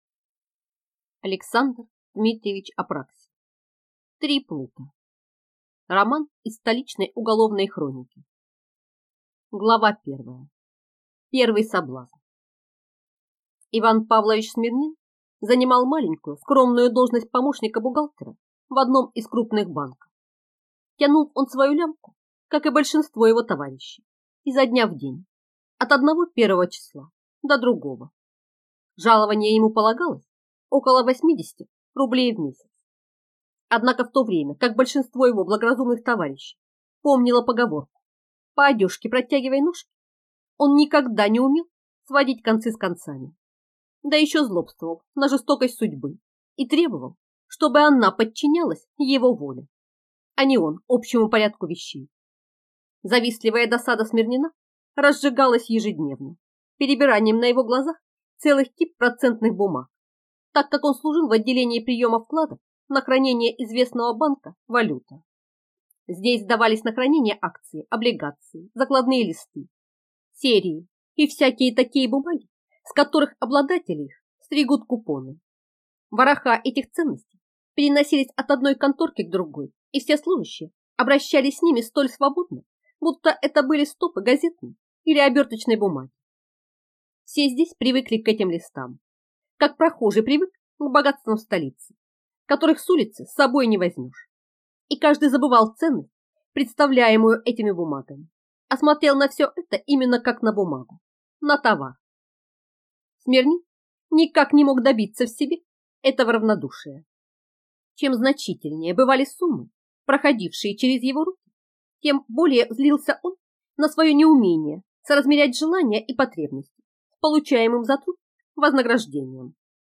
Аудиокнига Три плута | Библиотека аудиокниг